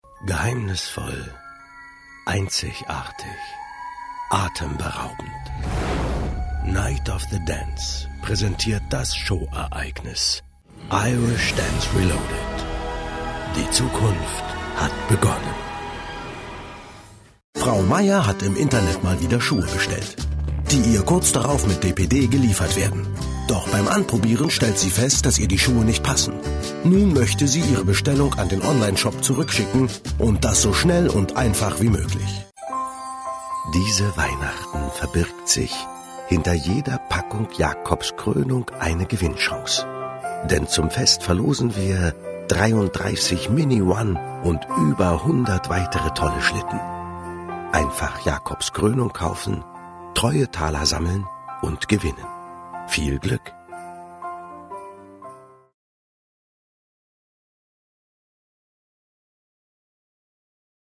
warme Stimme, Stimmlage Bariton, sehr flexibel
Sprechprobe: Werbung (Muttersprache):